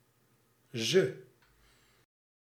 Ääntäminen
IPA: /zə/